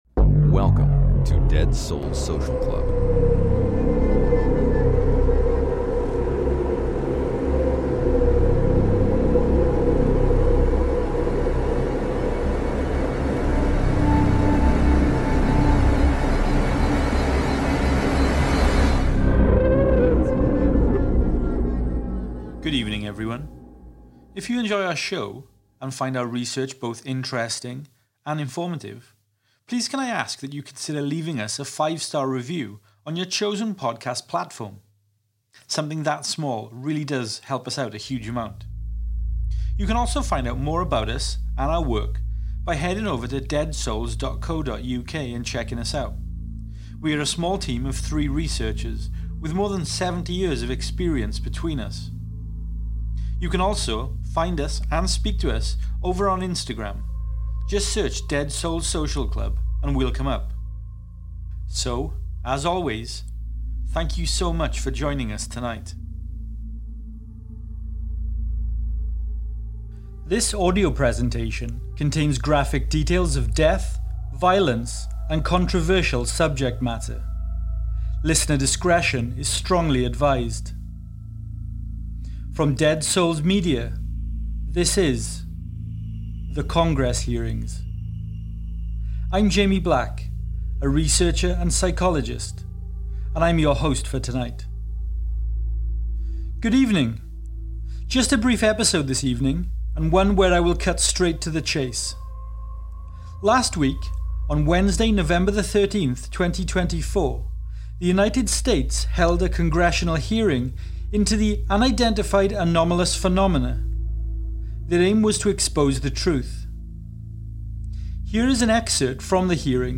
The Congressional Hearings.
Just a short episode tonight - We present some unedited audio of the US Congress hearings from November 13th, 2024. Luis Elizondo & Michael Shellenberger share their knowledge and experience with Congress.